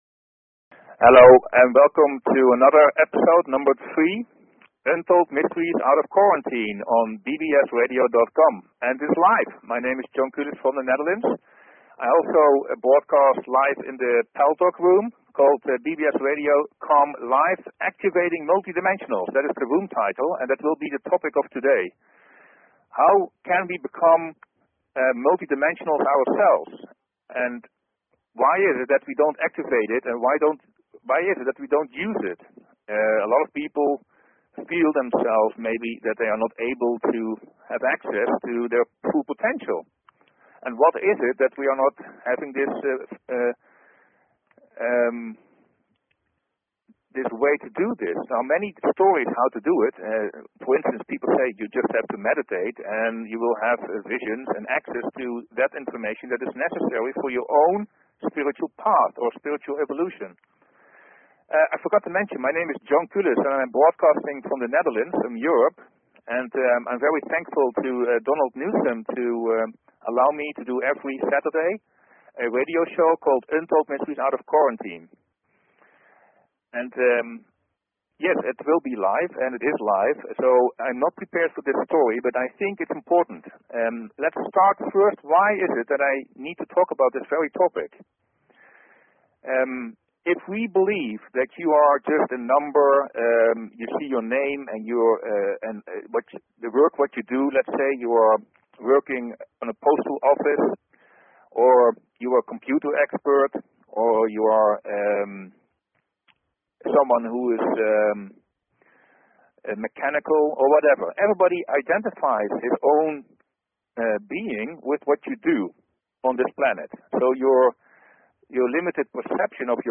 Talk Show Episode, Audio Podcast, UntoldMysteries and Courtesy of BBS Radio on , show guests , about , categorized as